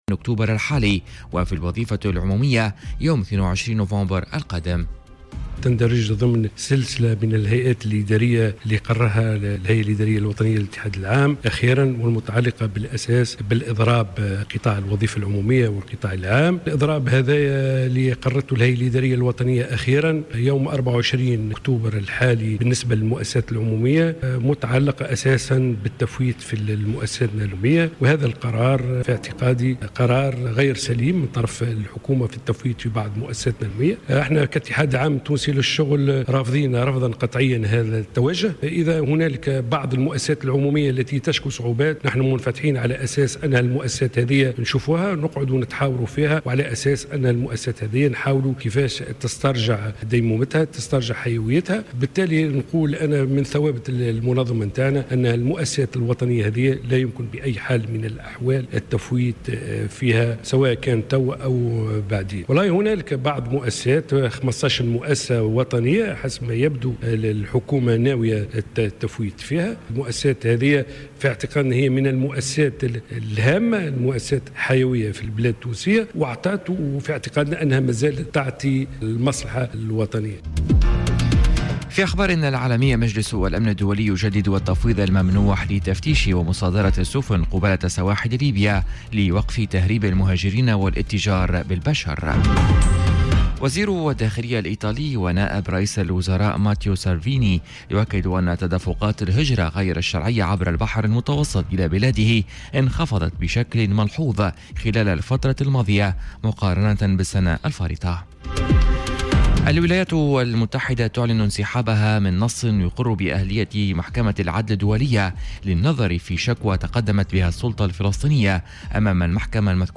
نشرة أخبار السابعة صباحا ليوم الخميس 04 أكتوبر 2018